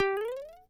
Sound effect of Super Mario Jump Low in Super Mario Bros. Wonder
SMBW_Super_Mario_Jump_Low.oga